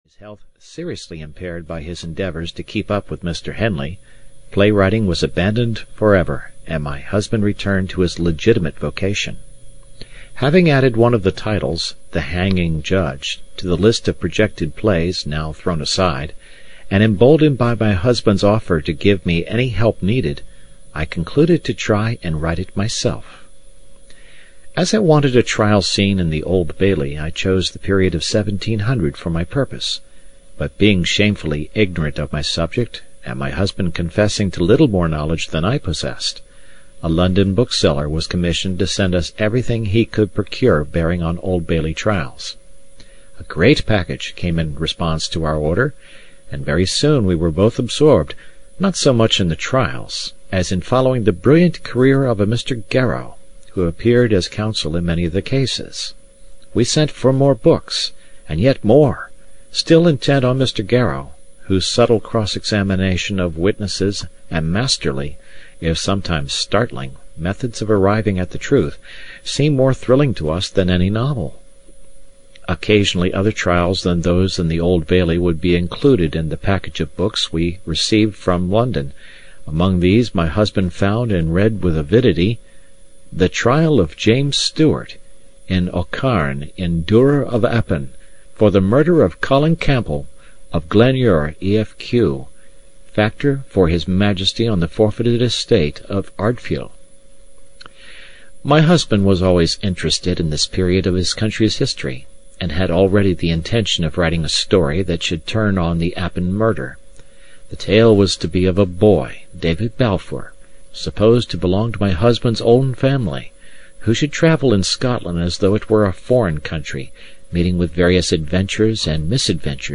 Kidnapped (EN) audiokniha
Ukázka z knihy